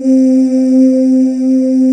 Index of /90_sSampleCDs/USB Soundscan vol.28 - Choir Acoustic & Synth [AKAI] 1CD/Partition C/05-ANGEAILES